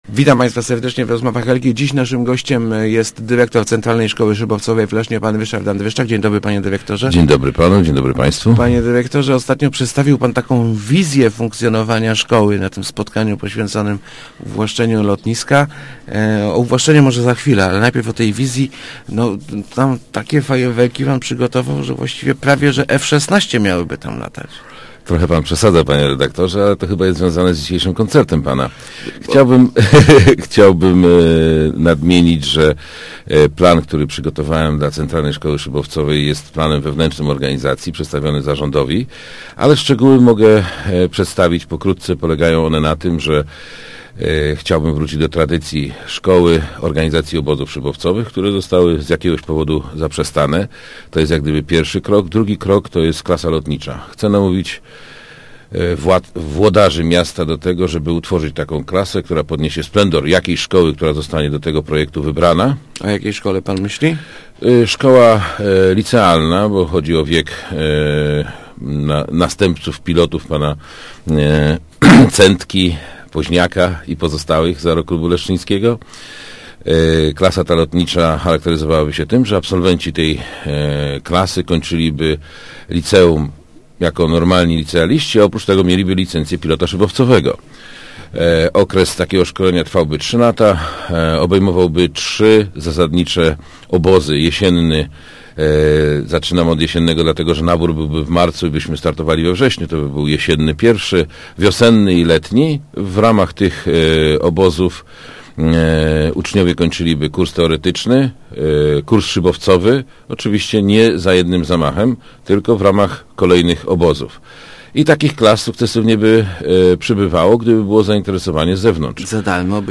06.12.2009. Radio Elka